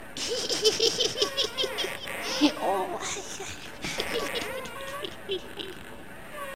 Laughing Witch (or Old Woman) in a Rocking Chair
creepy evil horror laugh laughing old old-woman scary sound effect free sound royalty free Funny